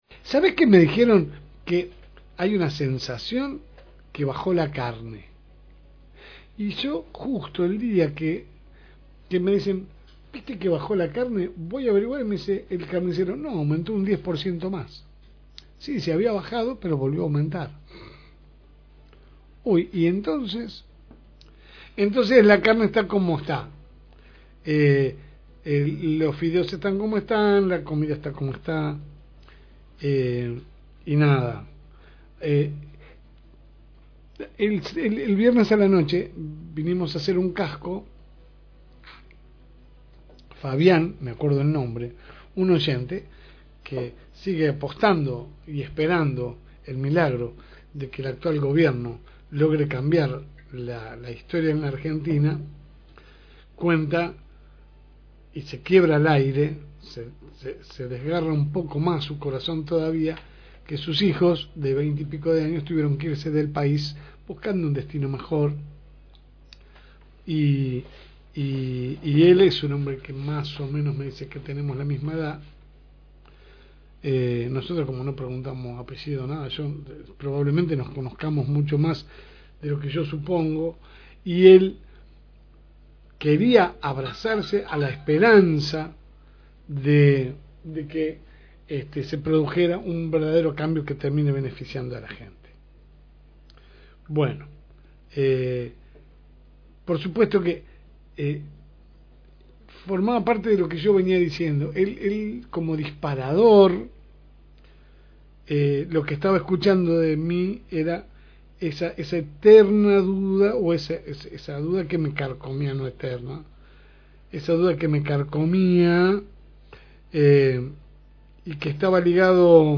Escucha el editorial completa aquí